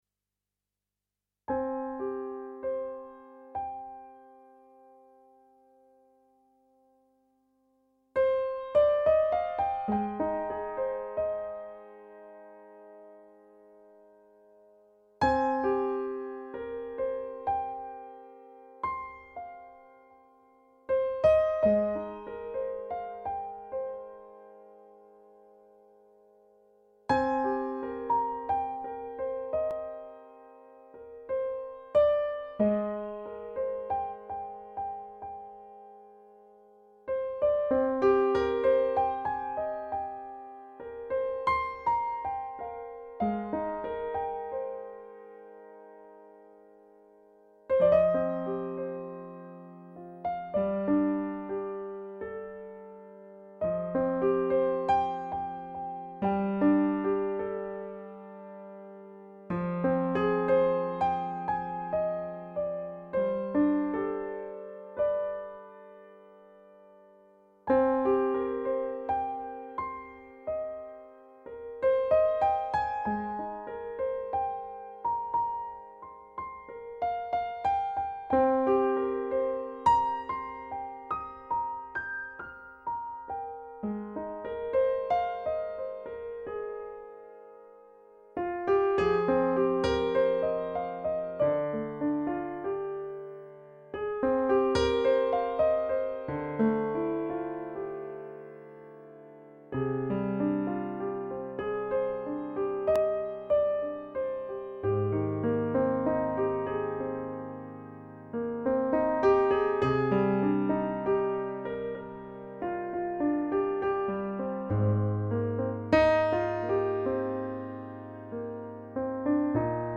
I hope you enjoy it, especially if you missed the live session.
So beautiful and powerful melody.